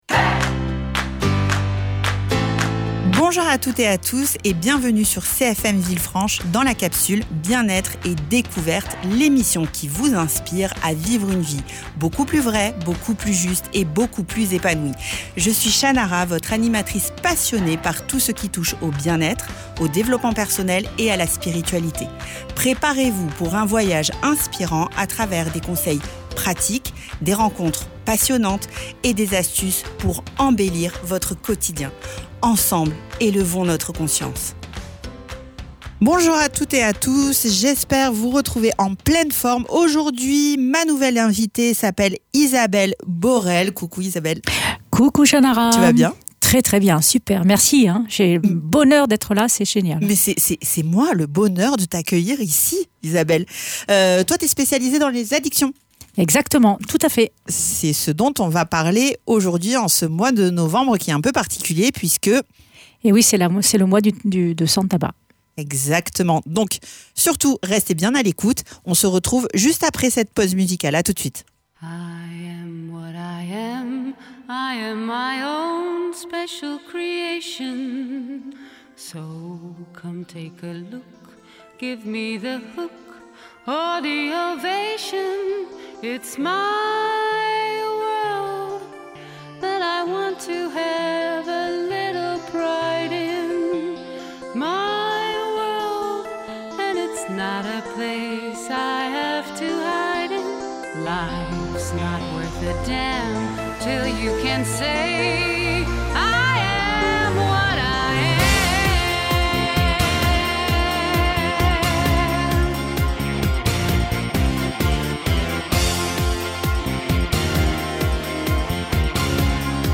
Dans l’émission d’aujourd’hui, je reçois ma nouvelle invitée.